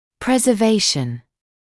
[ˌprezə’veɪʃn][ˌпрэзэ’вэйшн]сохранение